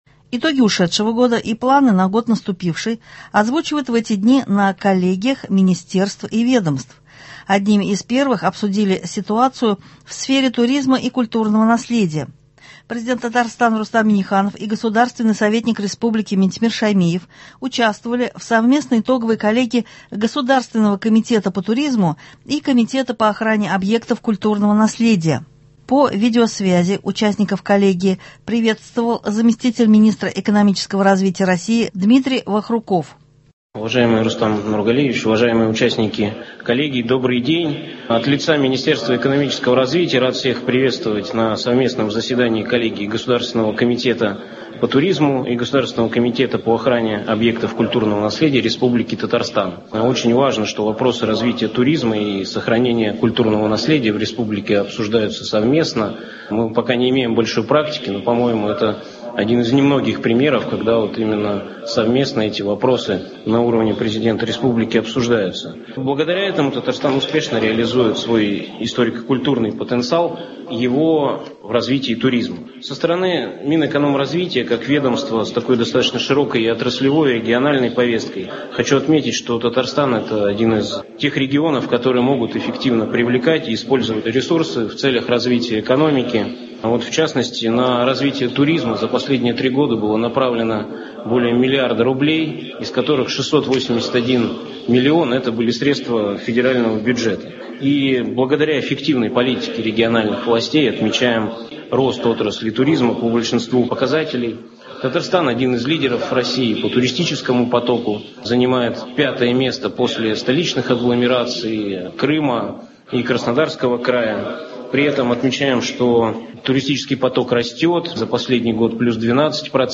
С зам. министра сельского хозяйства и продовольствия Ленаром Гариповым обсуждаем перспективы развития животноводства в 2023 г.